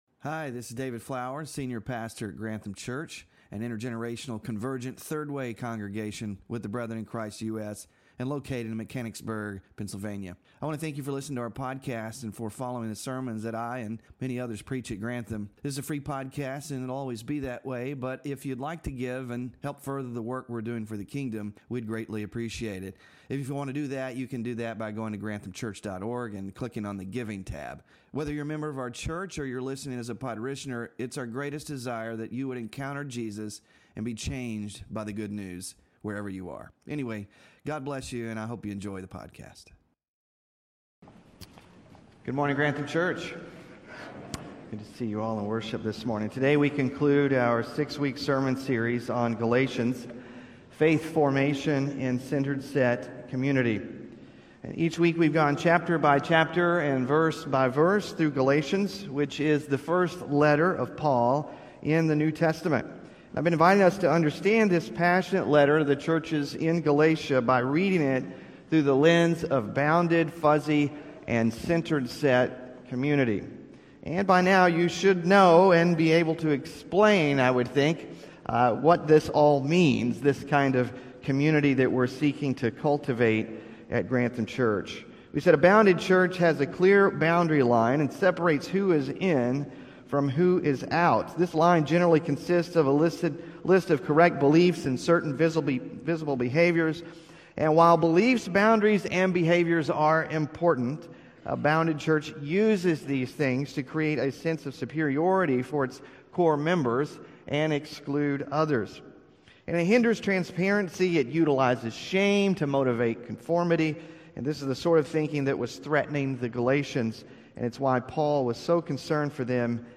In this concluding message of our series